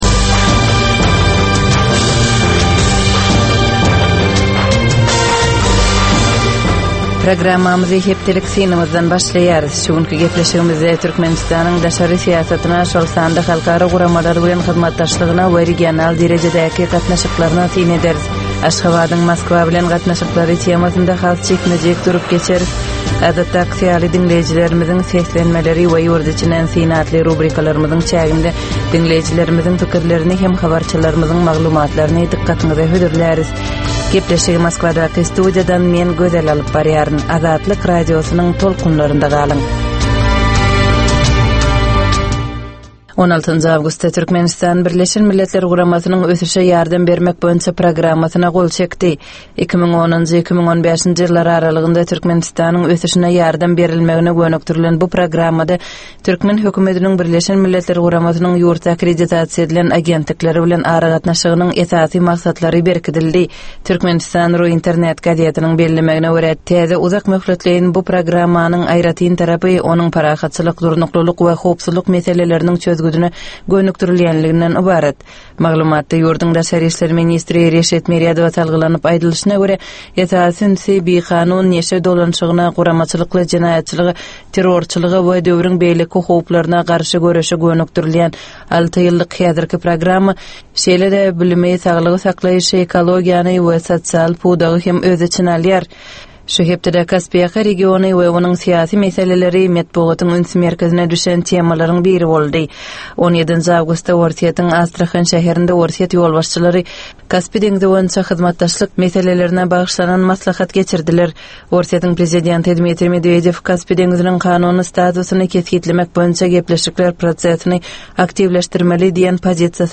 Tutus geçen bir hepdänin dowamynda Türkmenistanda we halkara arenasynda bolup geçen möhüm wakalara syn. 25 minutlyk bu ýörite programmanyn dowamynda hepdänin möhüm wakalary barada gysga synlar, analizler, makalalar, reportažlar, söhbetdeslikler we kommentariýalar berilýar.